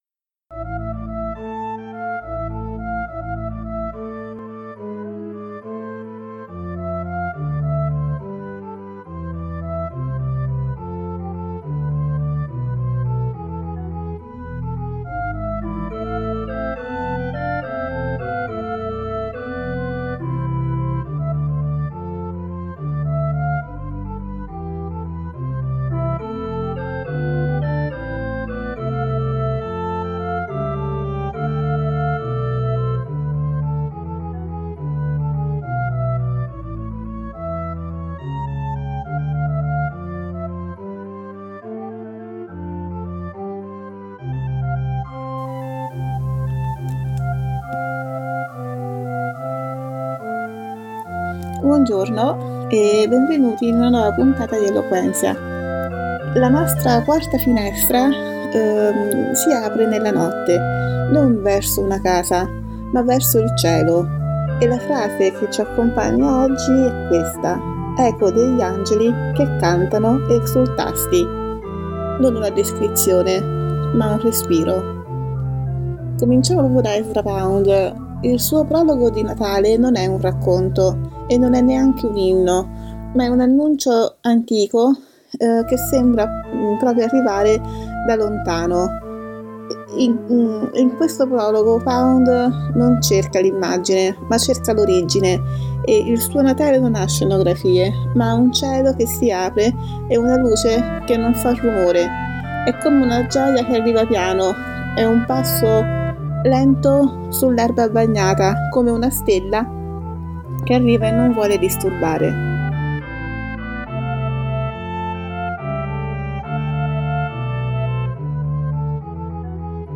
La musica che ascoltiamo oggi è una piccola chicca. Arriva da un canto antichissimo dei Paesi Baschi e si chiama Gabriel’s Message.